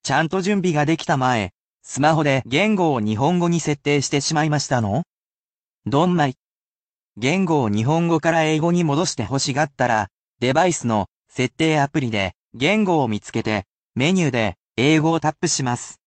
As a bilingual computer, I can relate to this topic.
[basic polite speech]